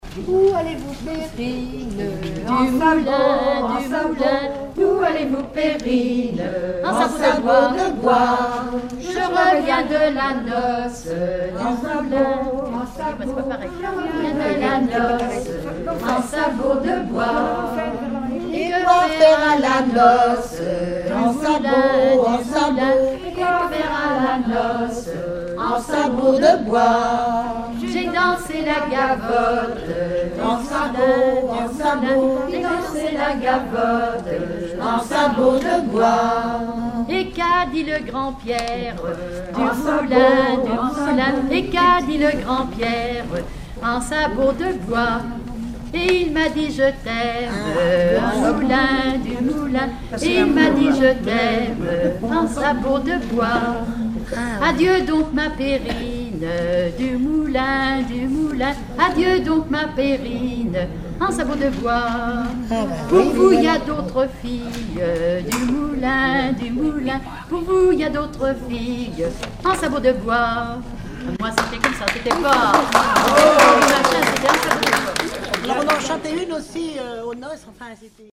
Couplets à danser
Regroupement de chanteurs du canton
Pièce musicale inédite